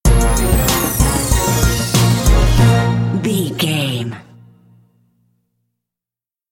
Aeolian/Minor
B♭
drums
percussion
strings
conga
brass